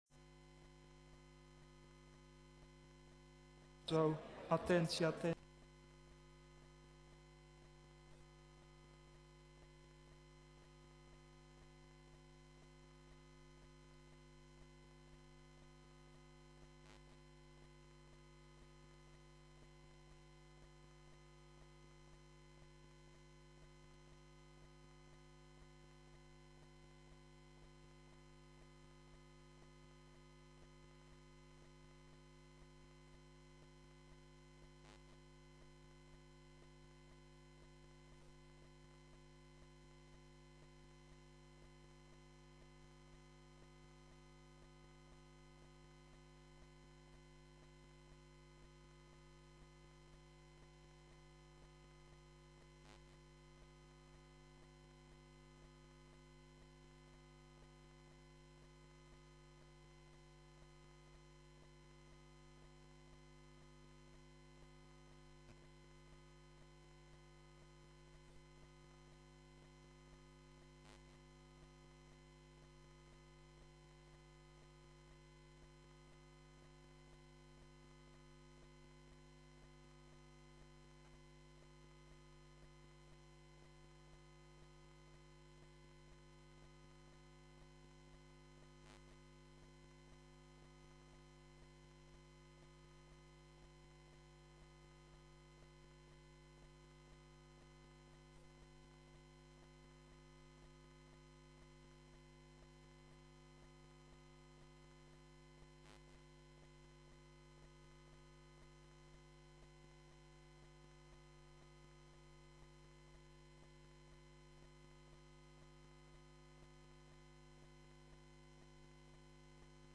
Gemeenteraad 27 maart 2014 19:30:00, Gemeente Goirle
Locatie: Raadzaal